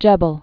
(jĕbəl)